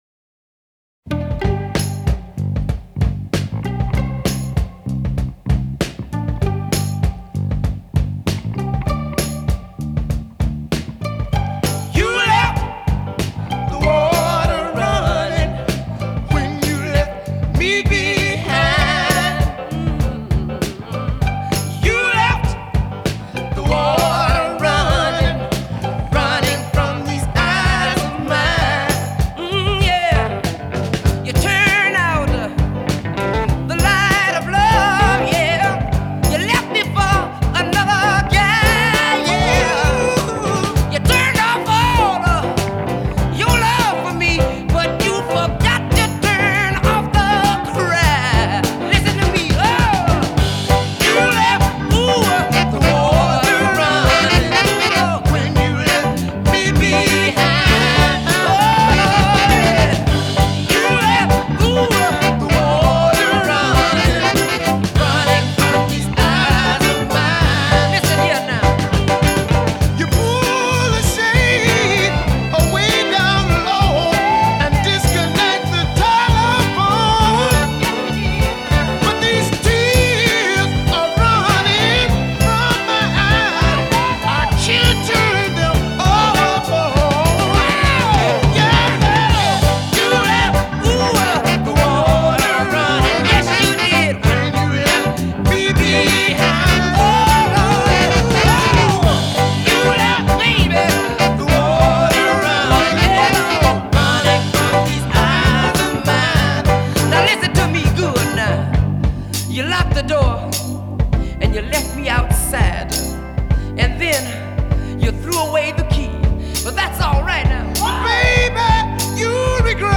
The light jazz guitar, those great harmonies.